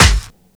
Lis Snare.wav